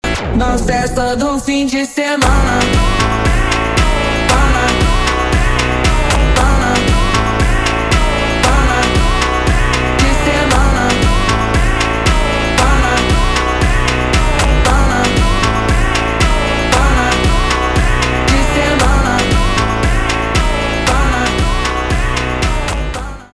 Клубные рингтоны
электронные
фонк